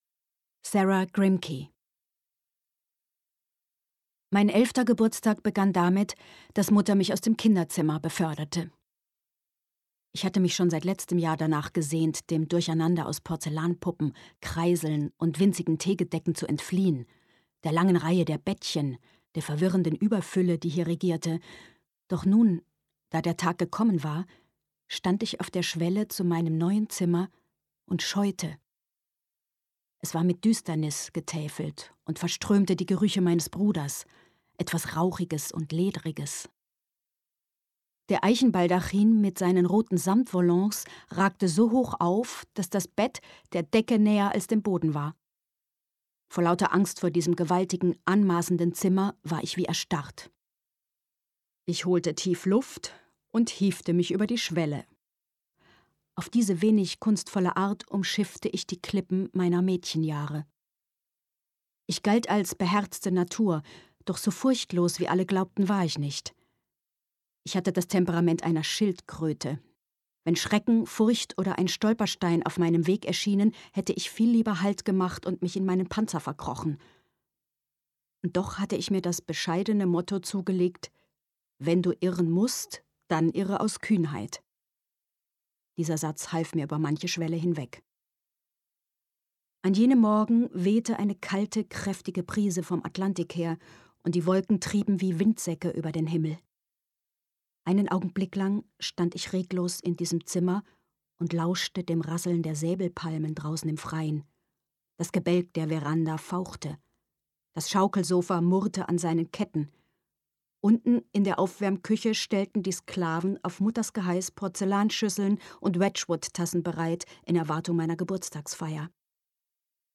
Inka Friedrich, Bibiana Beglau (Sprecher)
Audio/Hörbuch